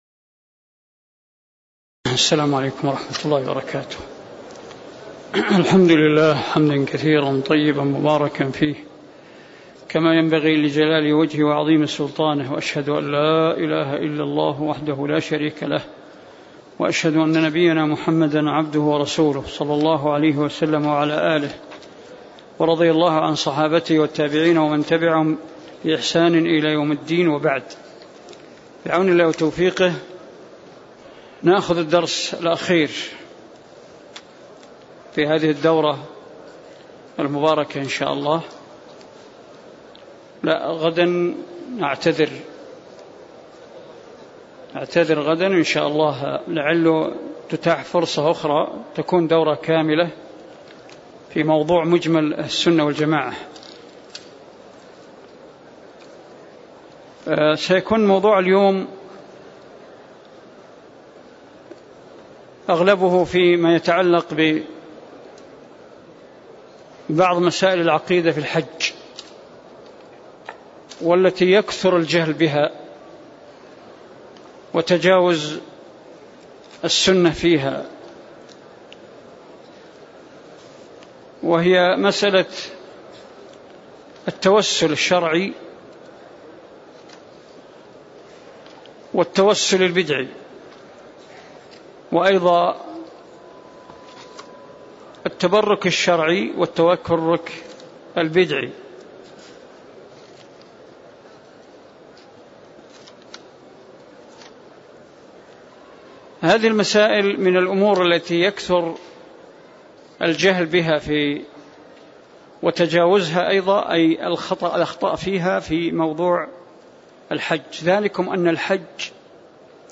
تاريخ النشر ١٧ ذو القعدة ١٤٣٦ هـ المكان: المسجد النبوي الشيخ